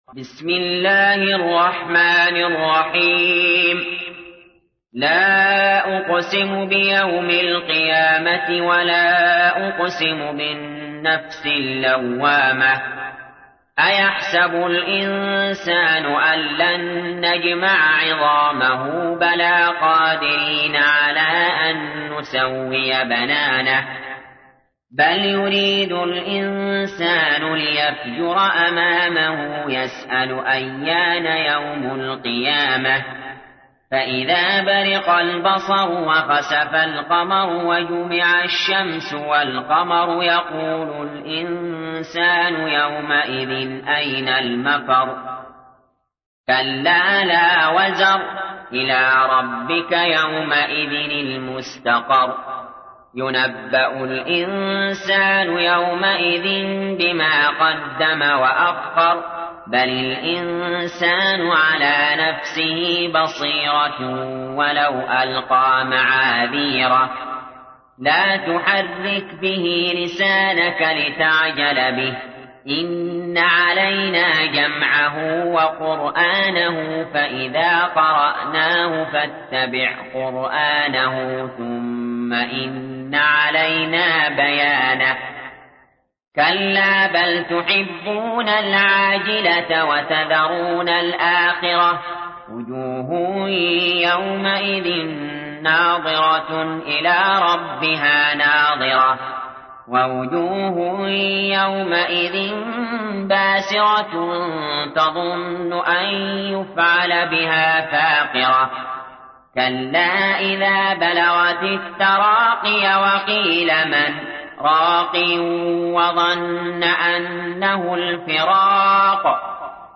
تحميل : 75. سورة القيامة / القارئ علي جابر / القرآن الكريم / موقع يا حسين